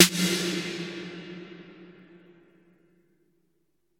Snare - Roland TR 46